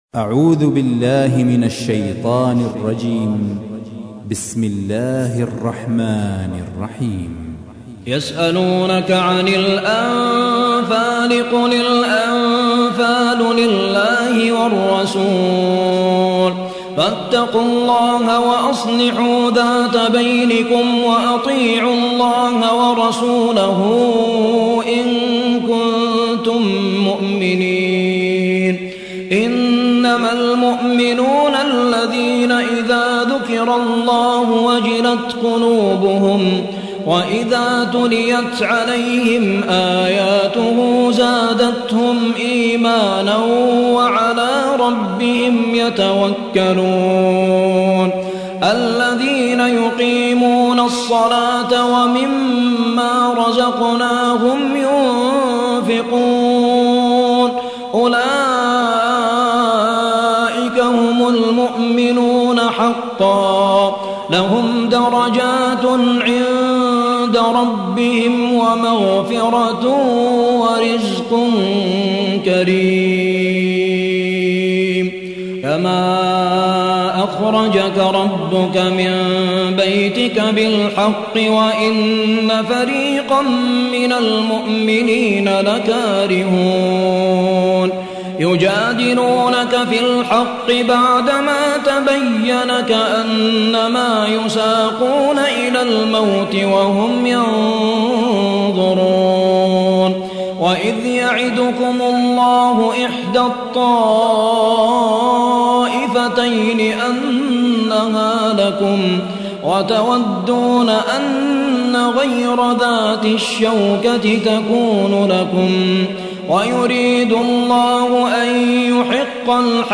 سورة الأنفال / القارئ